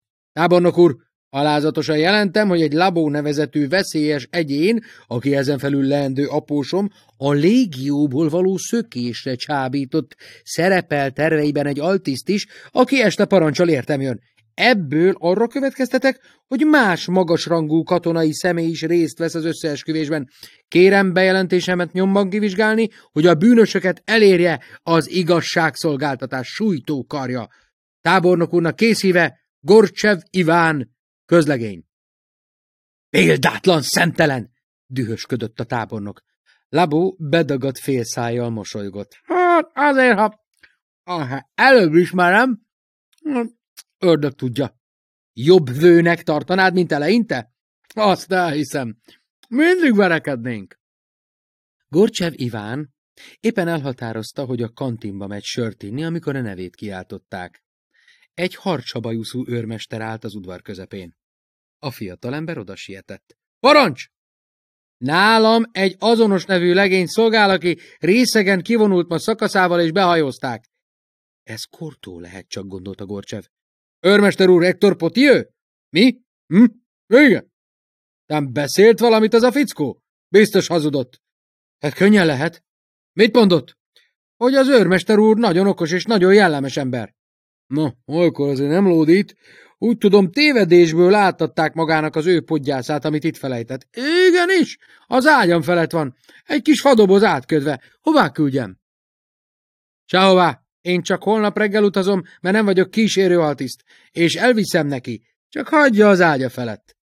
A tizennégykarátos autó (Online hangoskönyv) Csőre Gábor előadásában Rejtő Jenő (P. Howard) Hallgass bele!